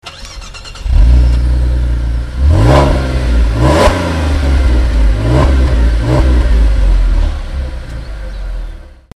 Sound vom Brera 3,2 V6